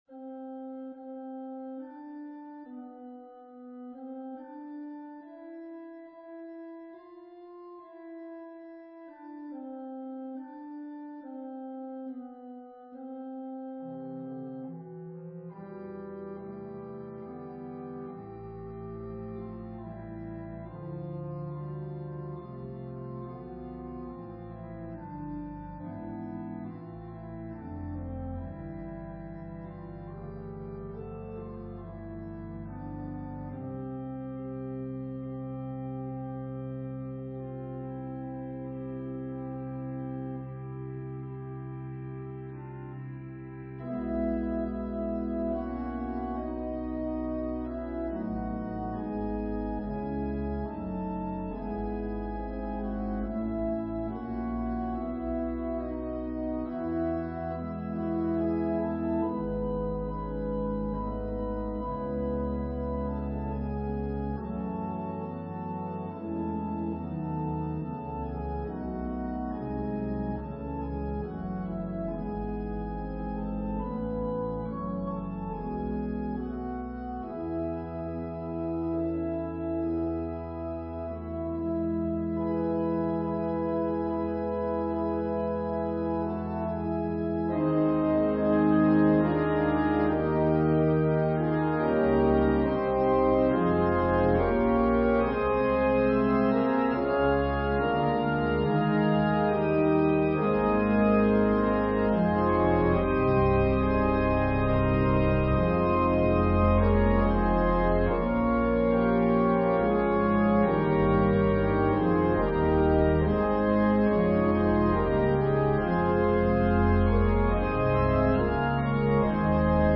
An organ solo version
Voicing/Instrumentation: Organ/Organ Accompaniment
Patriotic